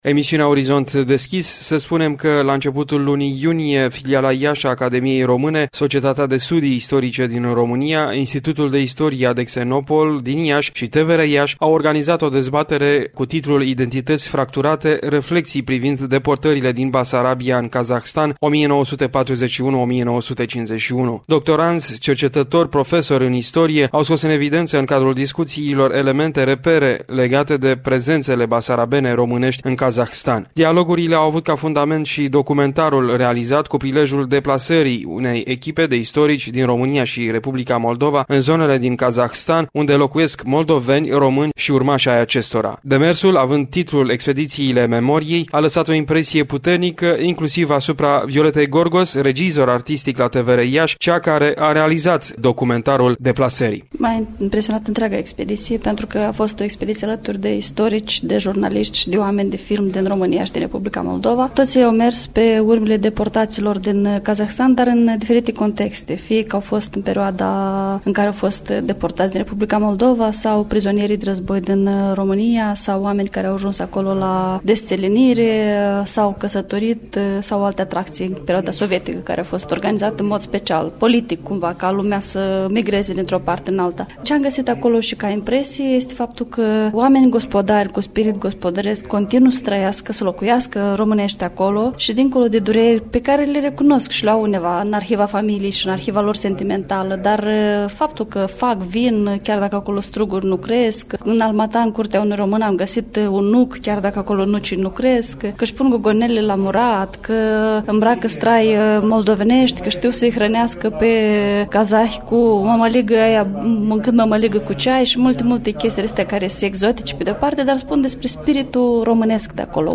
La începutul lunii iunie, Filiala Iași a Academiei Române, Societatea de Studii Istorice din România, Institutul de Istorie „A.D. Xenopol” din Iași și TVR Iași au organizat o dezbaterea cu titlul ”Identități fracturate. Reflecții privind deportările din Basarabia în Kazahstan, 1941-1951”. Doctoranzi, cercetători, profesori în istorie au scos în evidență, în cadrul discuțiilor, elemente, repere, legate de prezențele basarabene – românești în Kazahstan.